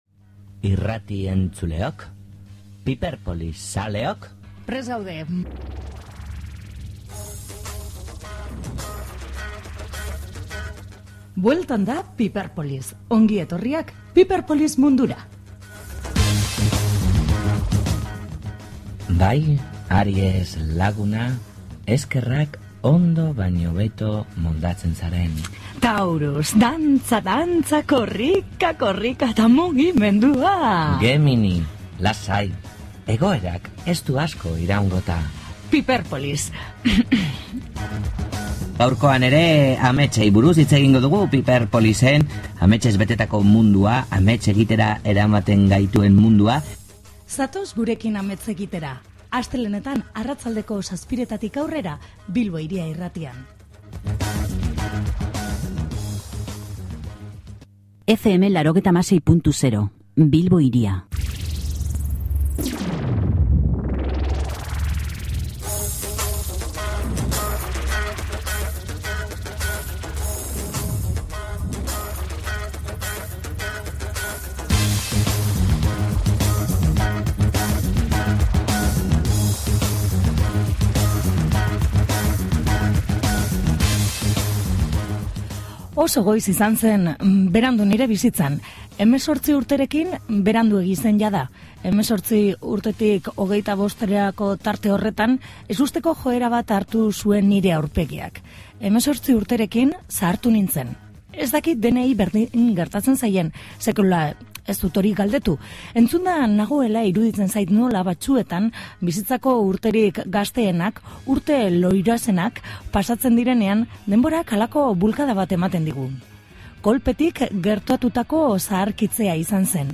Izaki Gardenak deituriko taldearen bisita izan dugu.
Sexty Sexers, Neubat eta Akatu taldeetako musikariek osatzen dute taldea; pop, rock eta country musikak dira ardatza.